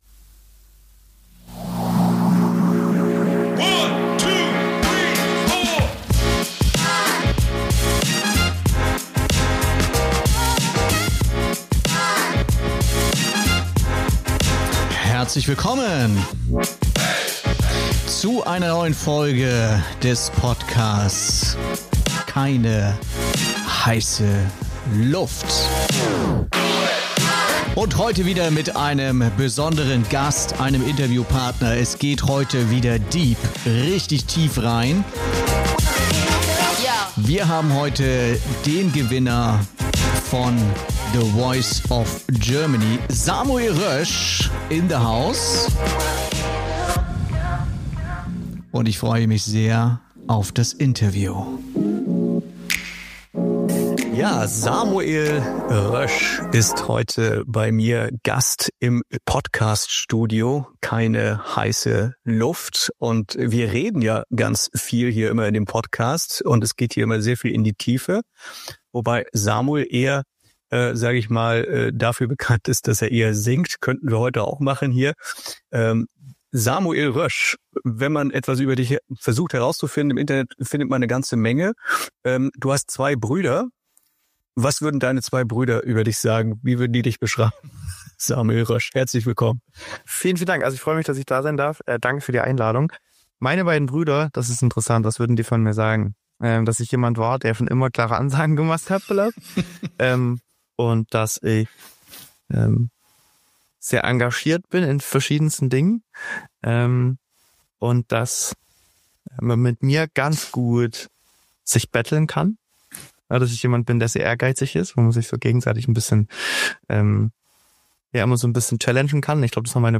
In dieser Folge von „Keine heiße Luft“ ist Samuel Rösch, Sänger, Songwriter und Gewinner von The Voice of Germany 2018, zu Gast.